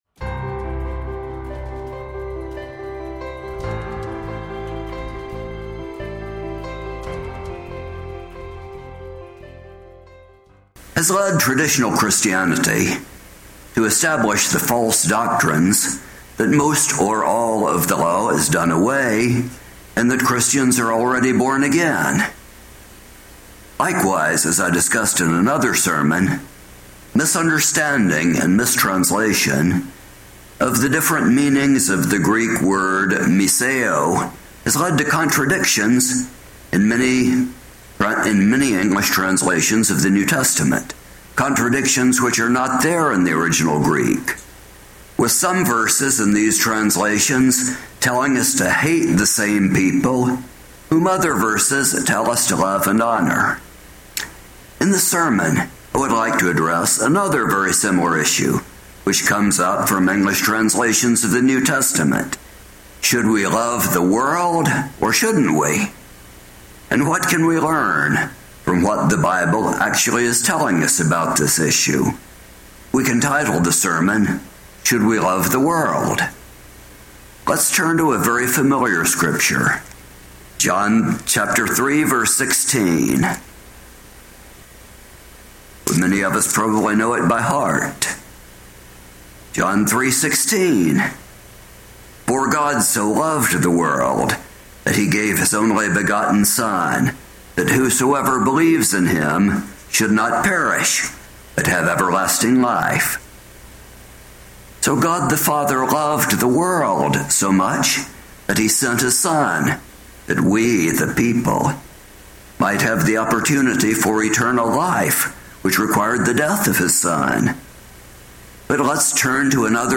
In this sermon, we will see what "world" we should love -- and what "world" we should not love and why.
Given in Roanoke and Kingsport on Sabbath, October 21, 2023